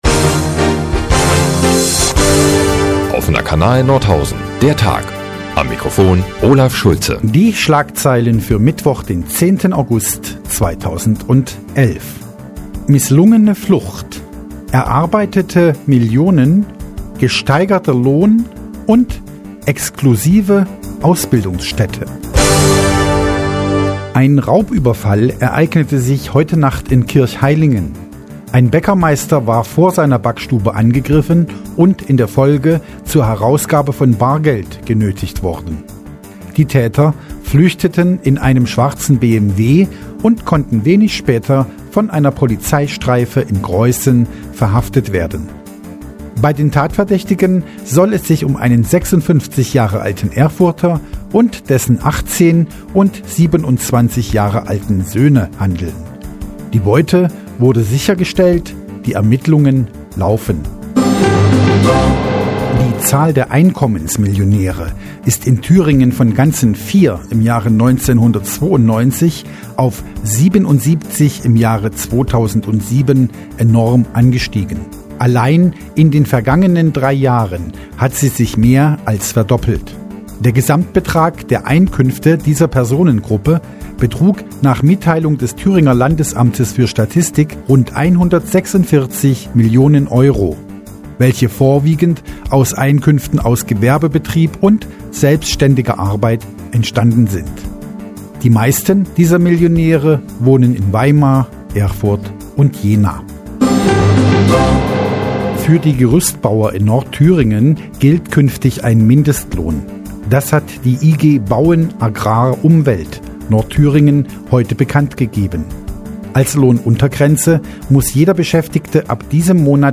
Die tägliche Nachrichtensendung des OKN ist nun auch in der nnz zu hören. Heute hören wir von einem Raubüberfall, einem Tarifveretrag, fleißigen Millionmären und einer exklusiven Ausbildungsstätte.